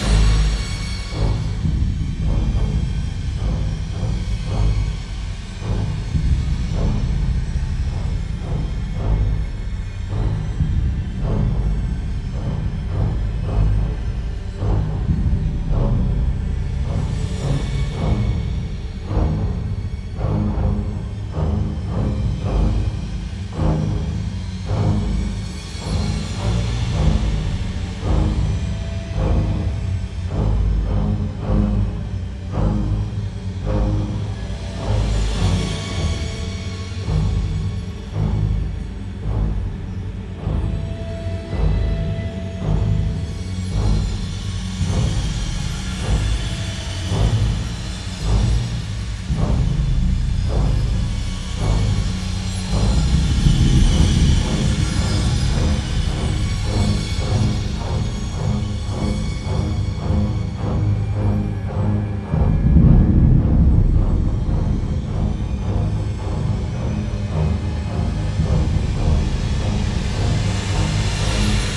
06 - Suspense